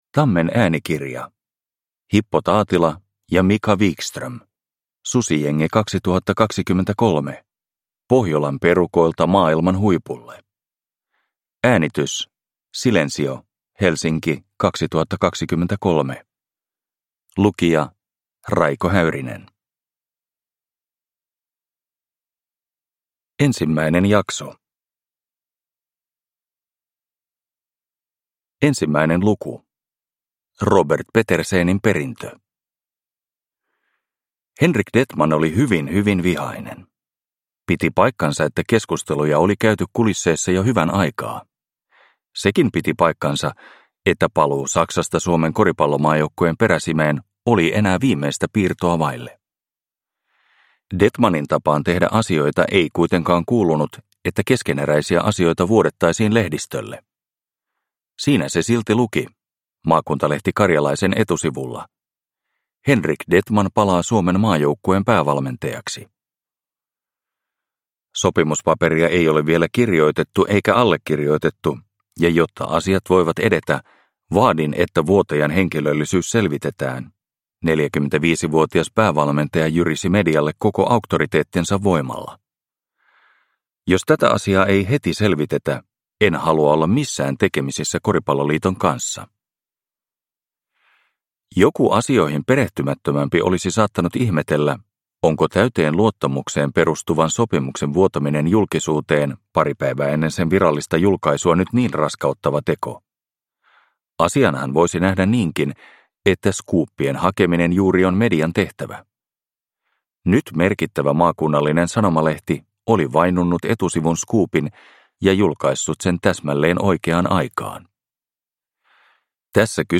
Susijengi 2023 – Ljudbok – Laddas ner